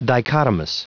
Prononciation audio / Fichier audio de DICHOTOMOUS en anglais
Prononciation du mot : dichotomous